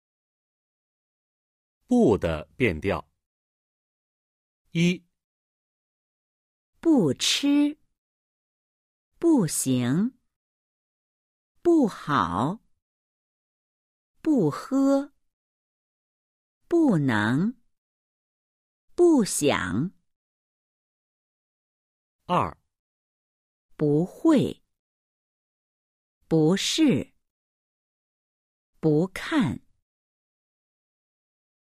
不 không thay đổi thanh điệu khi đứng trước âm tiết mang thanh 1, 2 hay 3.